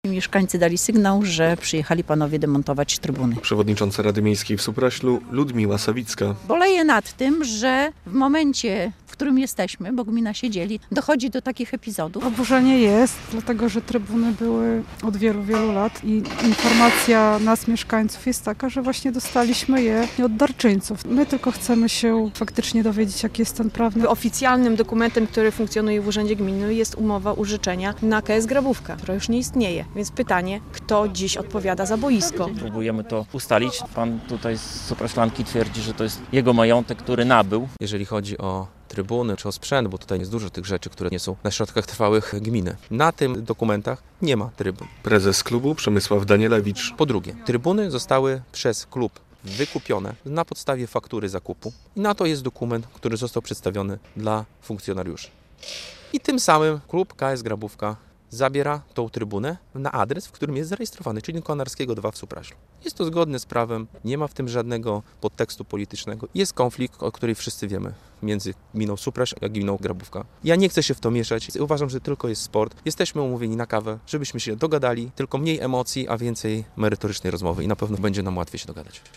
Radio Białystok | Wiadomości | Wiadomości - Grabówka kontra Supraśl: spór o trybuny na stadionie